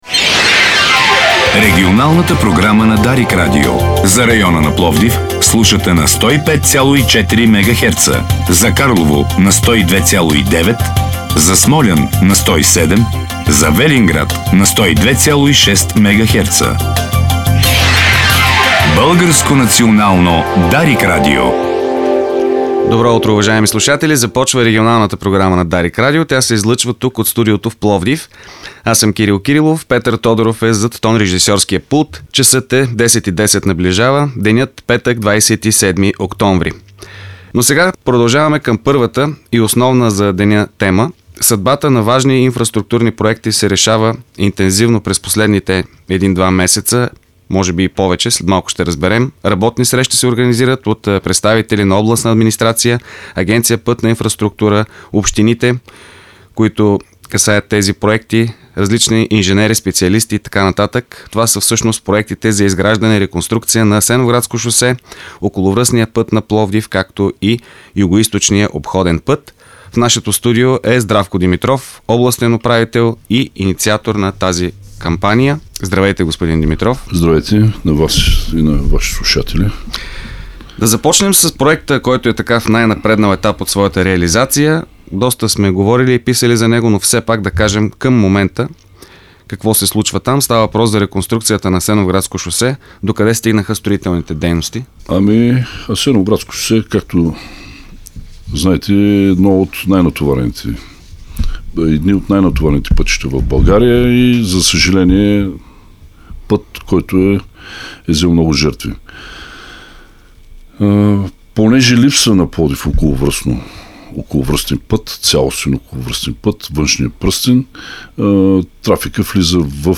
Това заяви в ефира на Дарик радио Областният управител Здравко Димитров по повод проектите, които се осъществяват за разширяването на пътищата около Пловдив.